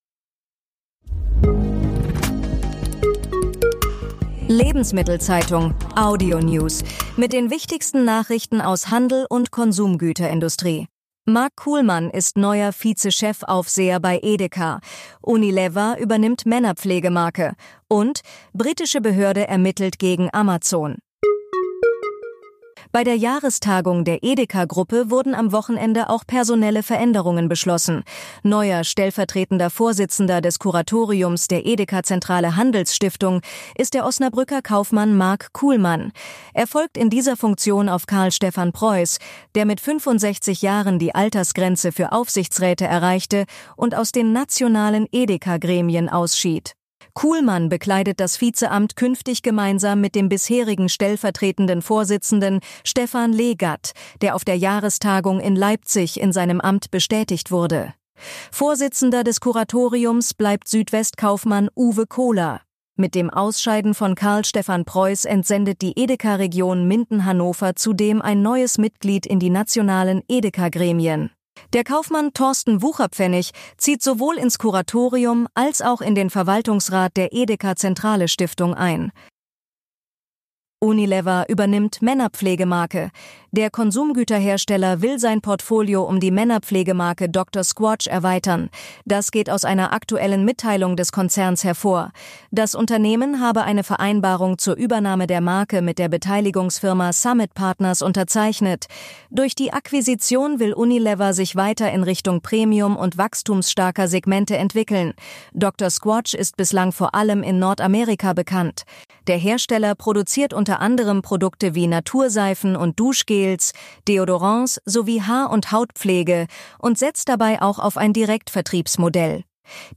Tägliche Nachrichten